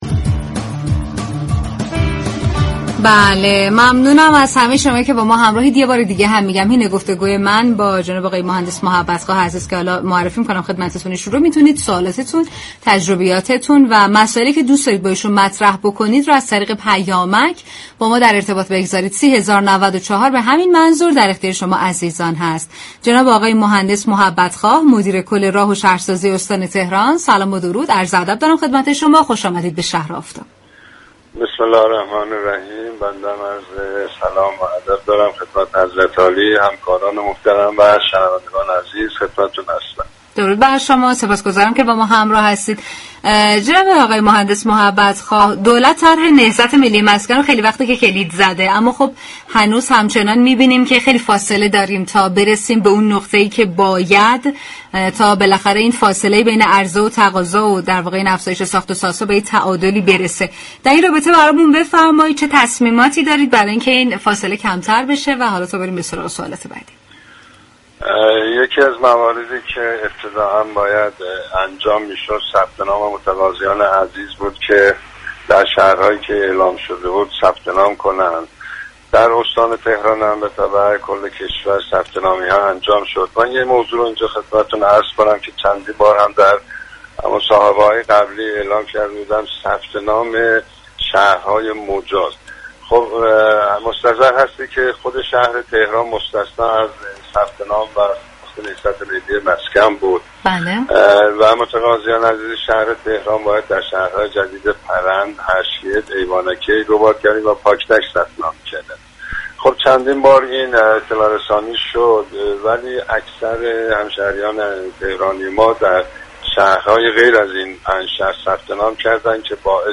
به گزارش پایگاه اطلاع رسانی رادیو تهران، خلیل محبت خواه مدیركل راه و شهرسازی استان تهران در گفت‌وگو با شهر آفتاب رادیو تهران گفت: ساخت واحدهای مسكونی نهضت ملی مسكن در مرحله فونداسیون است.